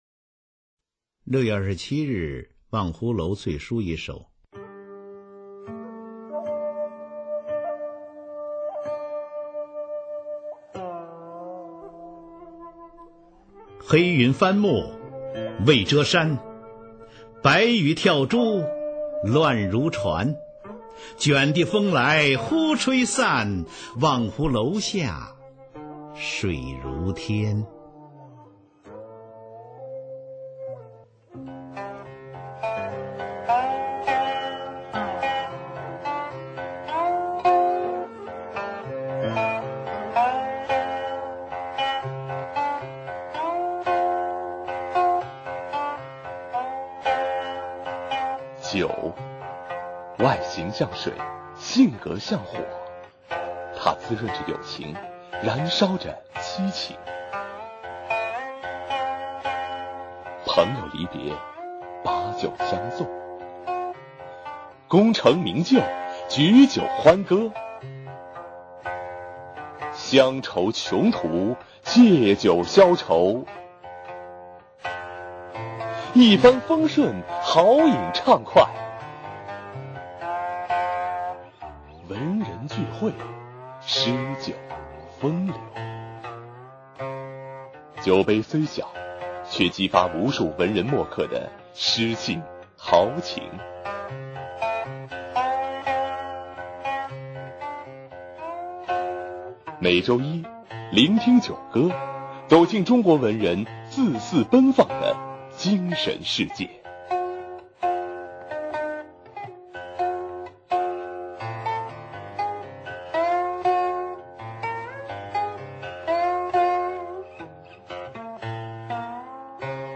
[宋代诗词诵读]苏轼-饮湖上初晴后雨 宋词朗诵